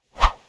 Close Combat Swing Sound 35.wav